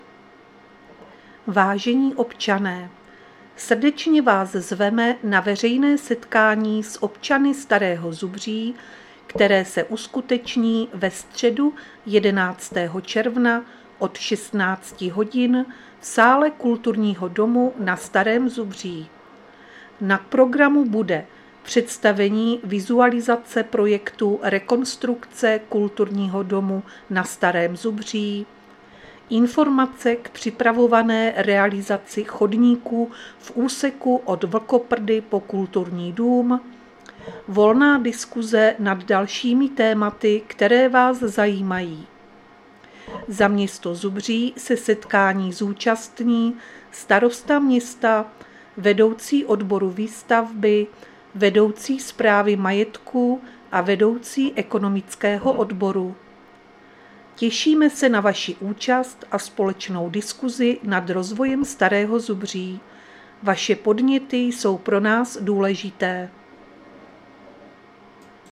Záznam hlášení místního rozhlasu 11.6.2025
Zařazení: Rozhlas